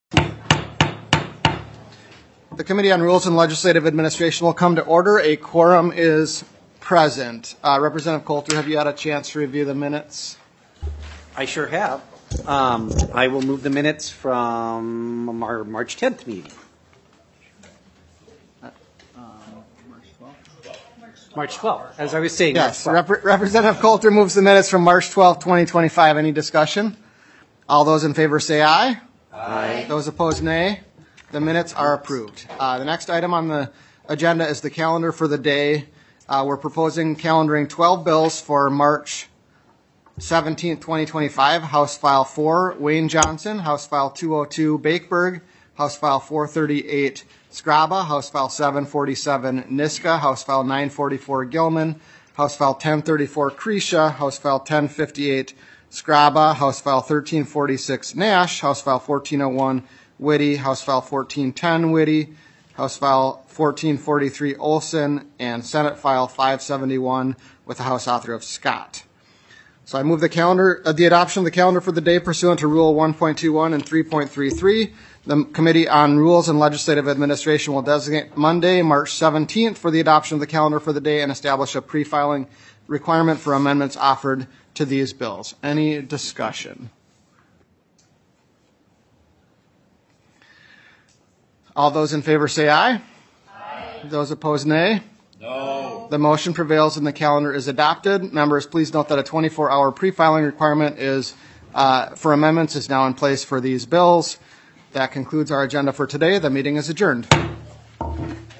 Meetings are listed by date with the most recent meeting at the top.